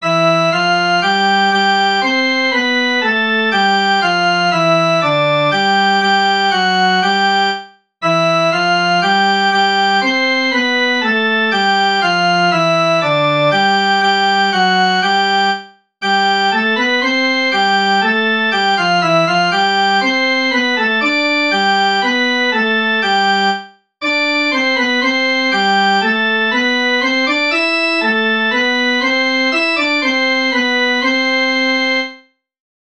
Melodie